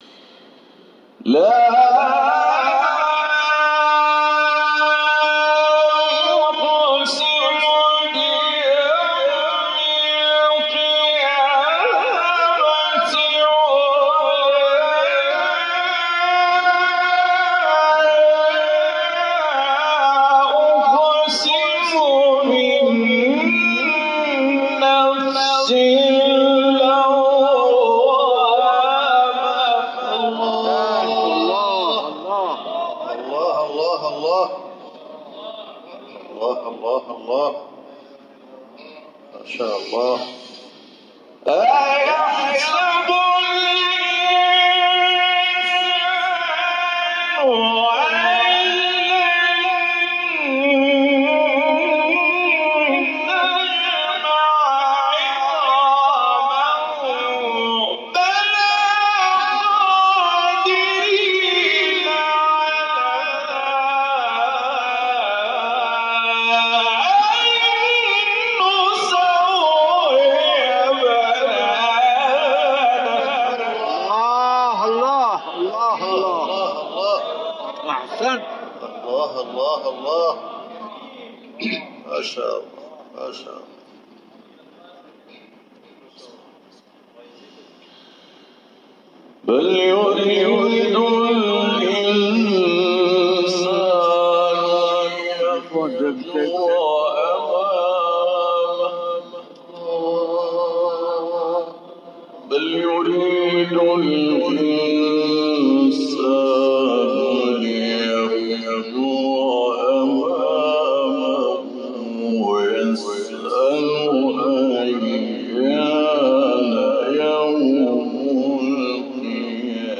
برگزاری محفل قرآنی حسینیه نجف اشرف قم به مناسبت هفته وحدت + صوت
برچسب ها: هفته وحدت ، تلاوت قرآن ، محفل قرآن